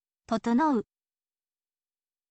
totonou